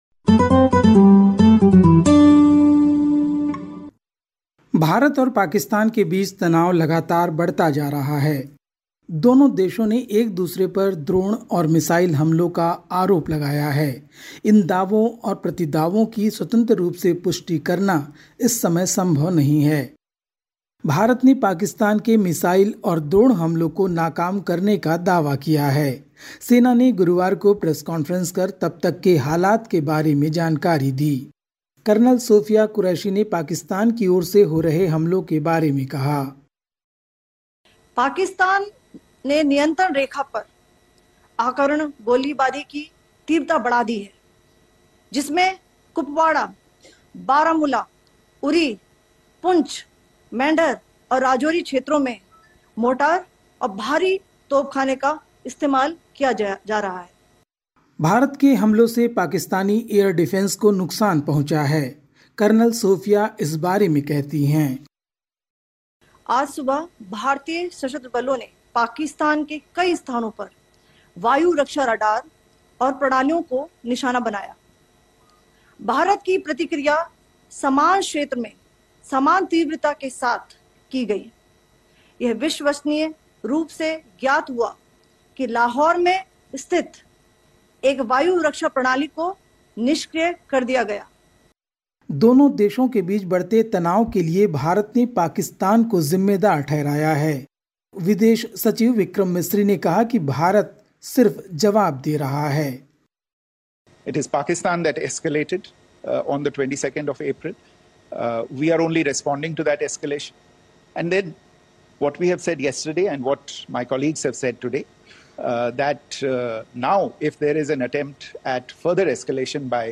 Listen to the latest SBS Hindi news from India. 09/05/2025